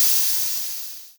terumet_venting.ogg